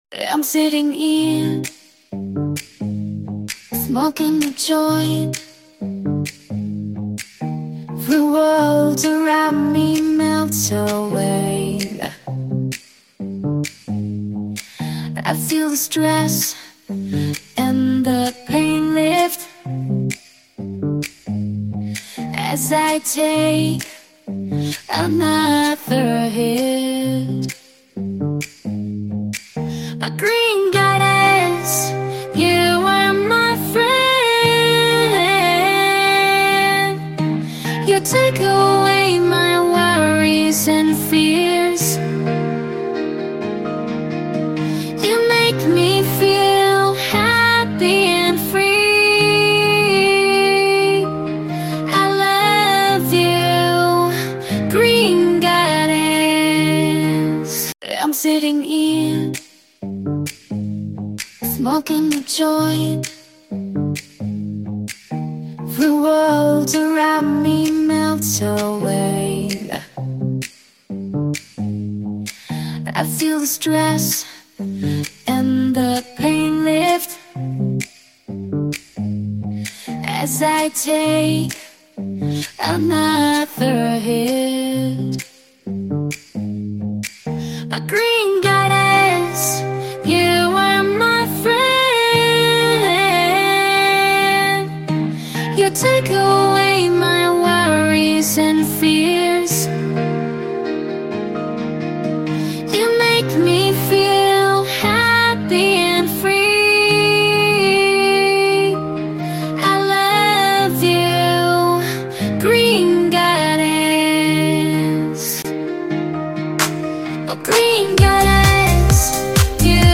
Gênero Funk.